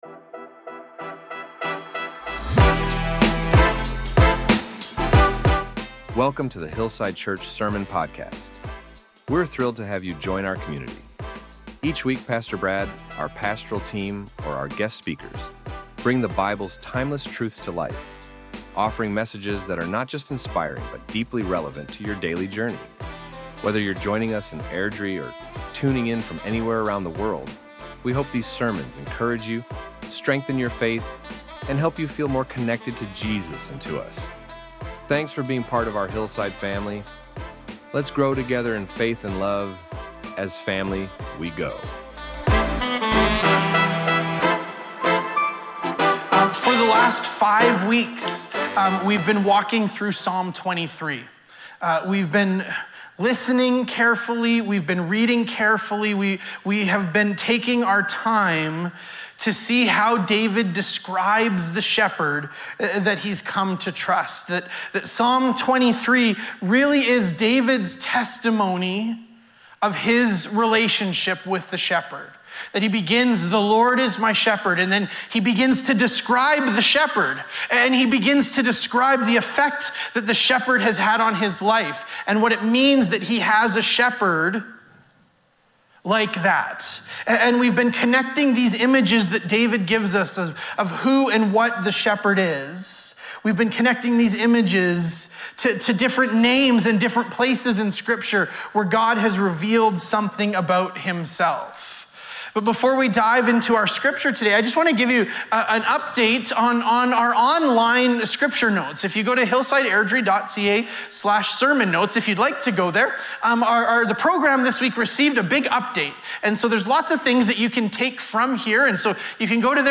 This sermon invited us to trust that healing is part of who God is, not just something He occasionally does.